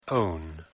پیش از اینکه وارد مباحث گرامری شویم به تلفظ این کلمه گوش دهید: